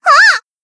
BA_V_Kotori_Cheerleader_Battle_Shout_3.ogg